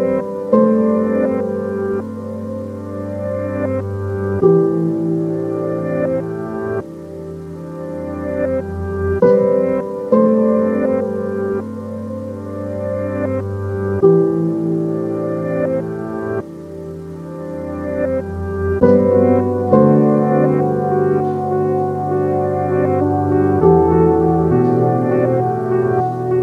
Category: Electro RIngtones